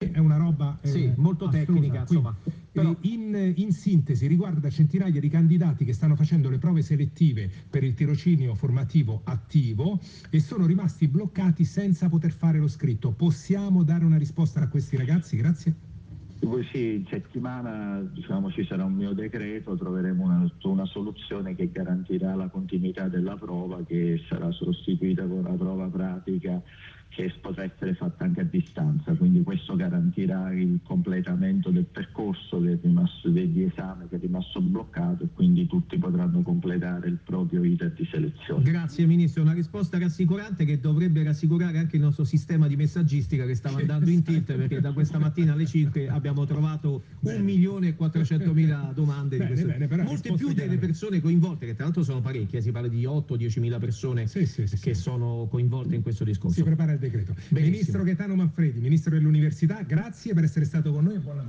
Intervista al Ministro dell’Università e della Ricerca prof. Gaetano Manfredi – Ascolta le parole del ministro Manfredi che parla di prova “scritta pratica” e modalità a distanza.